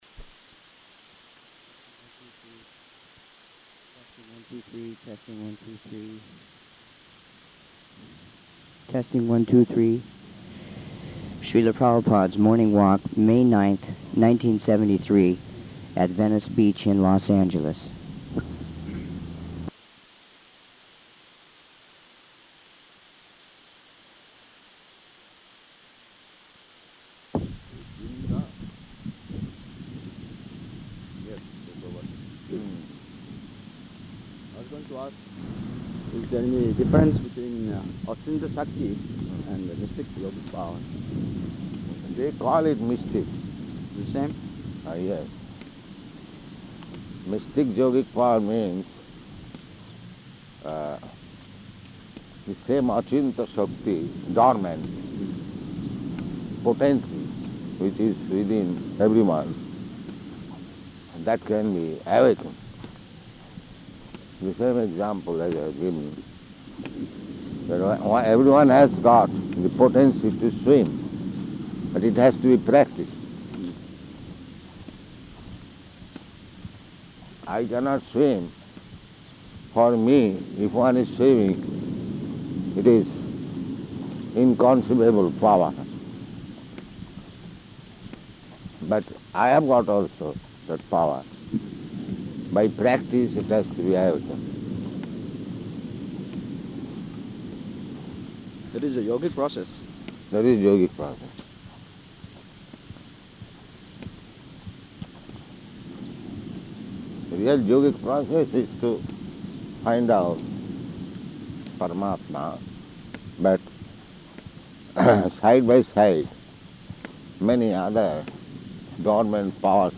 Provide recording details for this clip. Location: Los Angeles